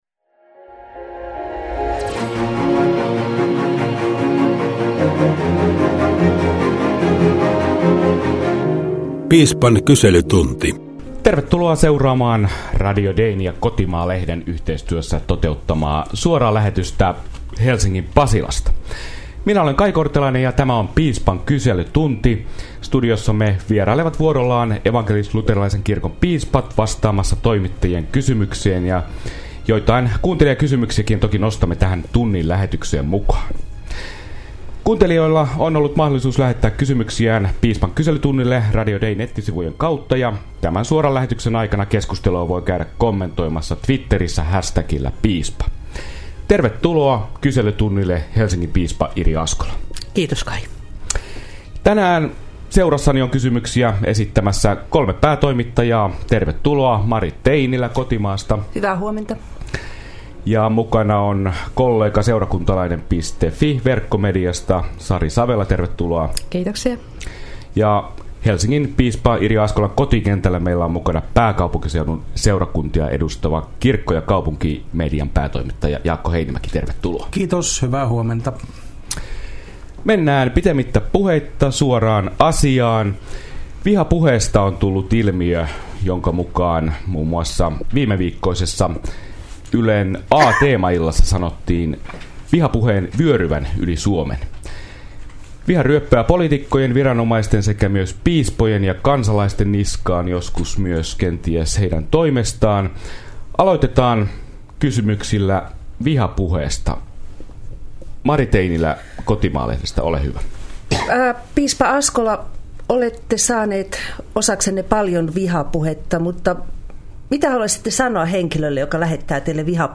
Toimittajat kysyvät – mitä piispa vastaa? Piispan kyselytunnilla keskiviikkoaamuna vastaajan paikalla Helsingin piispa Irja Askola. Radio Dein ja Kotimaa-lehden yhteistyössä toteuttama tunnin mittainen suora lähetys alkaa keskiviikkona 8.2. STT:n sähkeuutisten jälkeen kello 9.03.